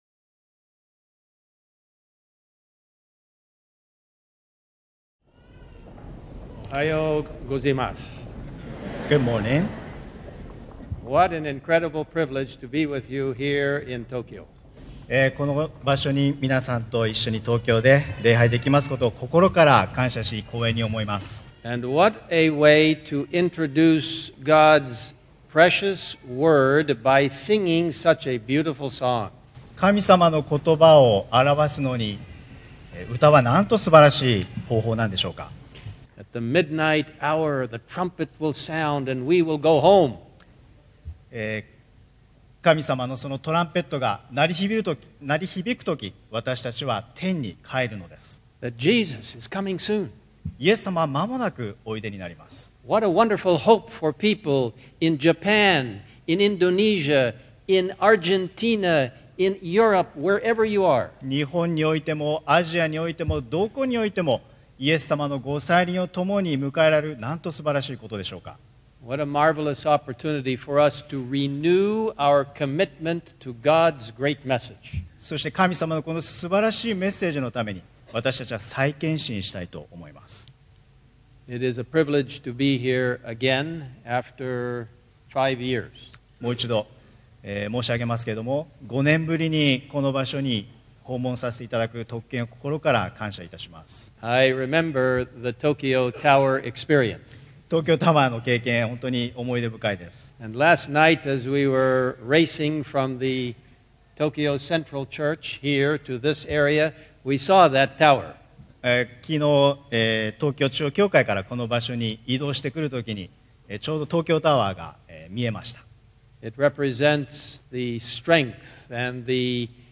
説教：テッド・ウィルソン世界総会総理